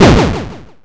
Explode_02.mp3